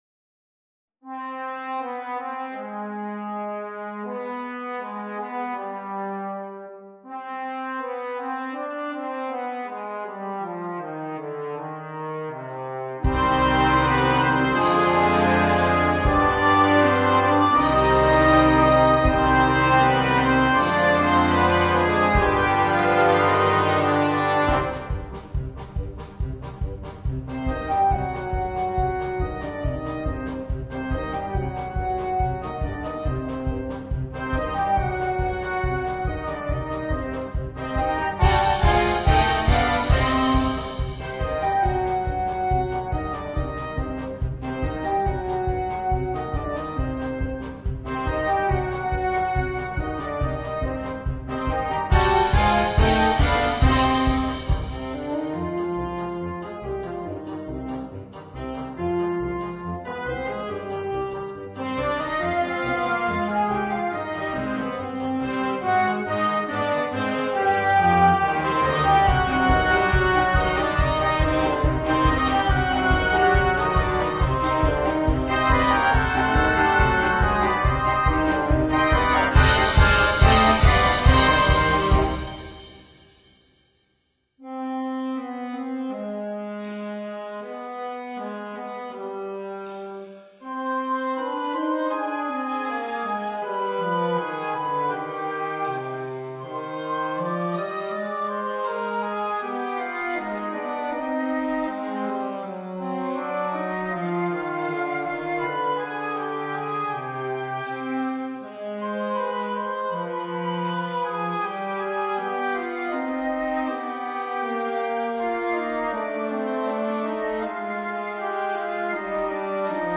for band